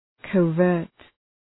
Shkrimi fonetik {‘kʌvərt}